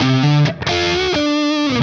AM_HeroGuitar_130-D02.wav